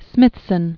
(smĭthsən), James 1765-1829.